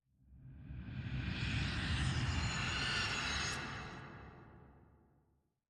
Minecraft Version Minecraft Version latest Latest Release | Latest Snapshot latest / assets / minecraft / sounds / ambient / nether / warped_forest / help1.ogg Compare With Compare With Latest Release | Latest Snapshot